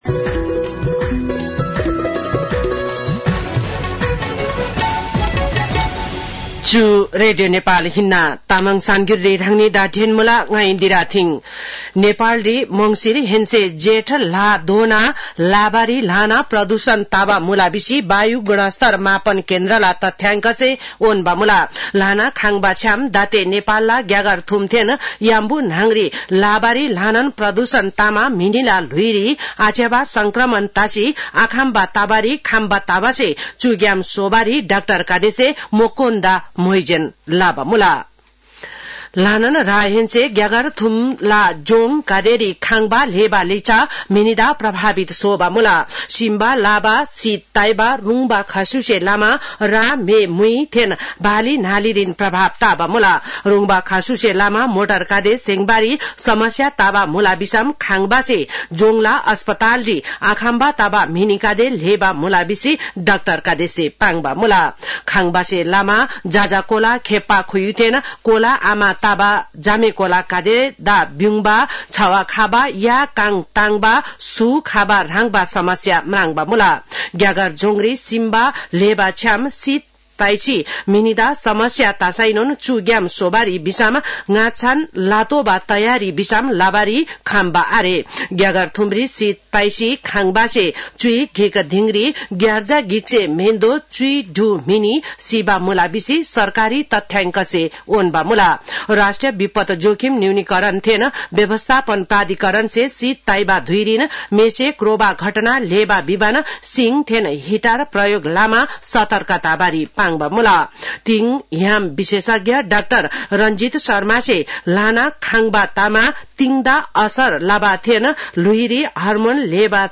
तामाङ भाषाको समाचार : २७ पुष , २०८१